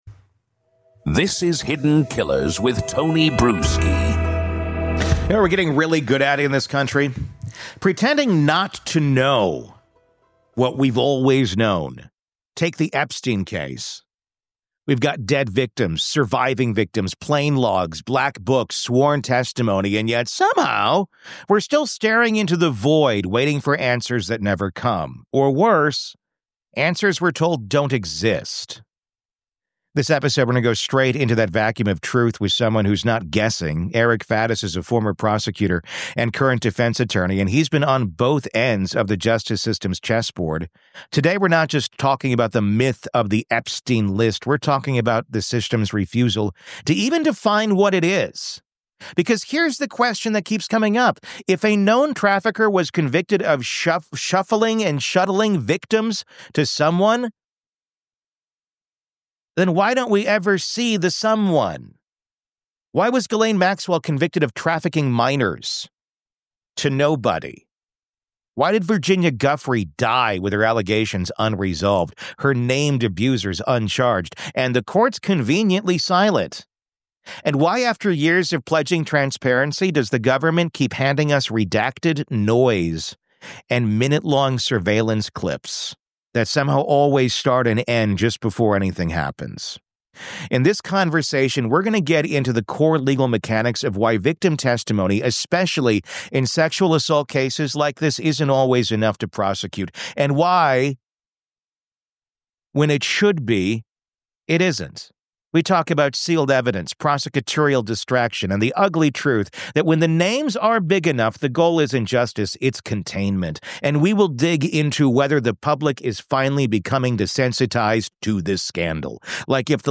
True Crime Today | Daily True Crime News & Interviews / Do You Believe There Really Is NO Jeffrey Epstein List?